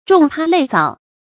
重葩累藻 注音： ㄓㄨㄙˋ ㄆㄚ ㄌㄟˋ ㄗㄠˇ 讀音讀法： 意思解釋： 比喻許多華麗的篇章。